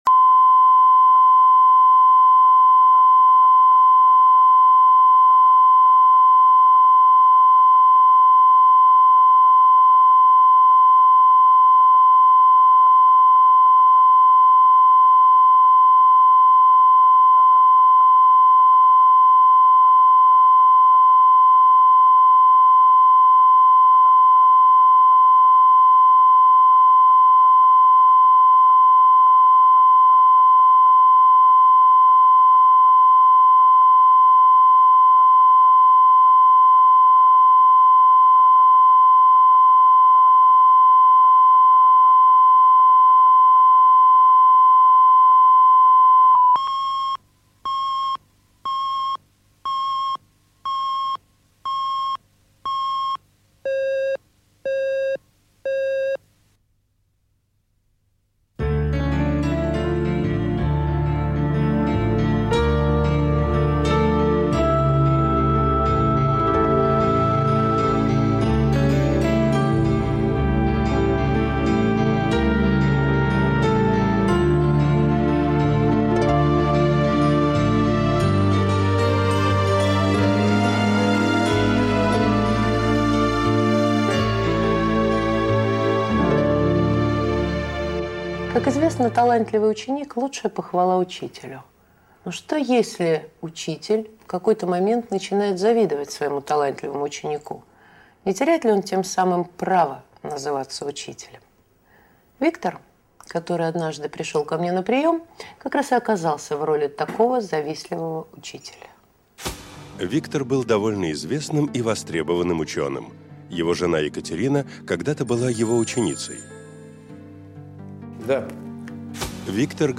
Аудиокнига Быть вместе | Библиотека аудиокниг